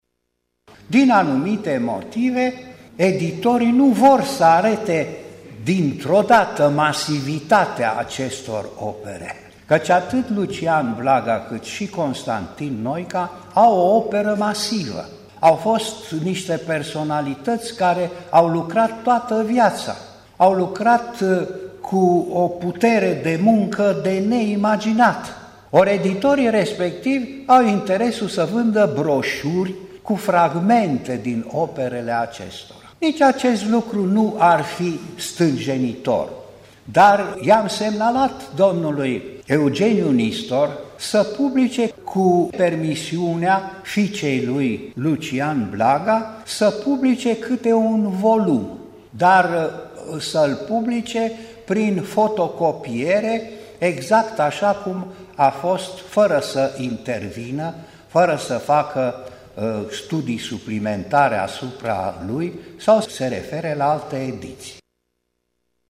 și academicianul Alexandru Surdu: